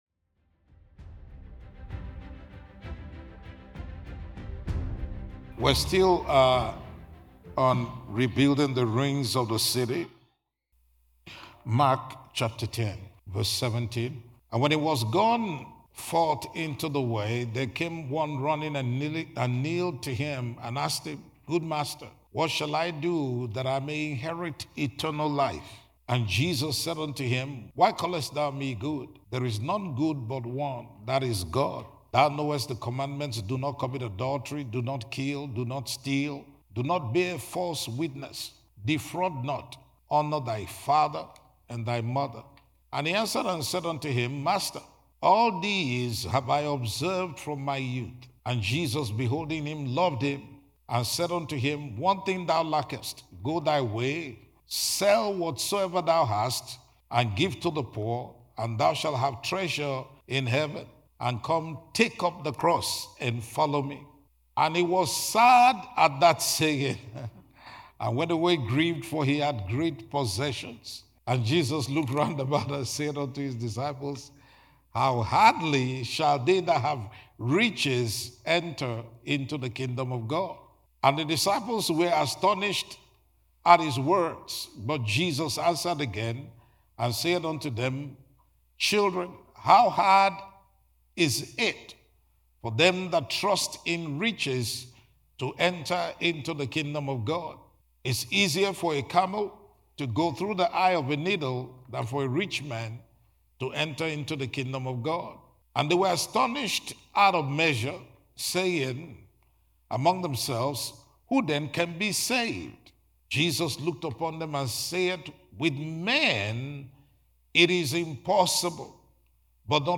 teaching series